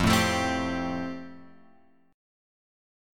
F Major Add 9th